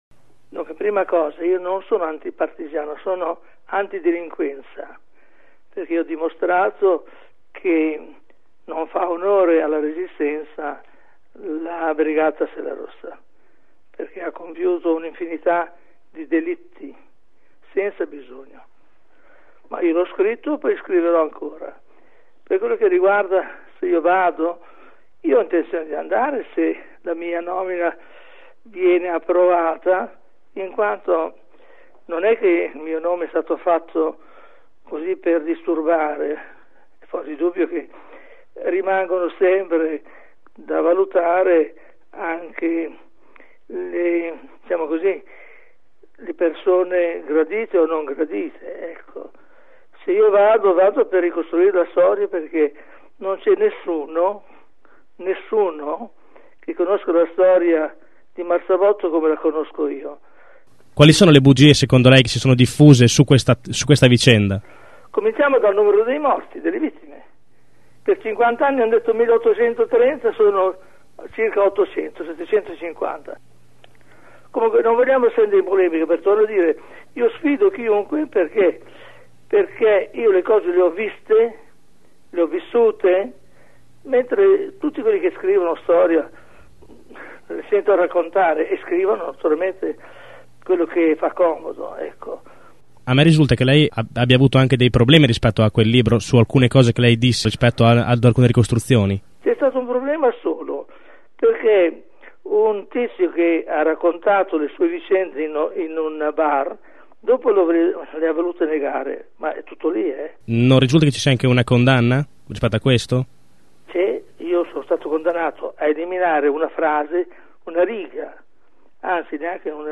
risponde così ai nostri microfoni